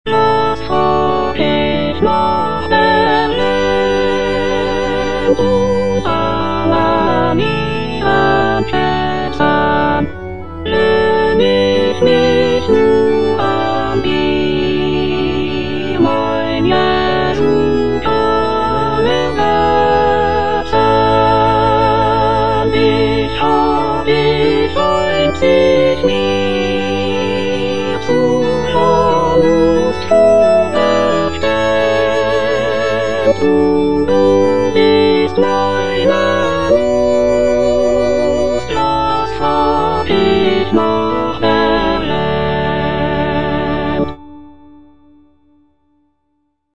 Choralplayer playing Cantata
J.S. BACH - CANTATA "SEHET, WELCH EINE LIEBE" BWV64 Was frag' ich nach der Welt - Soprano (Emphasised voice and other voices) Ads stop: auto-stop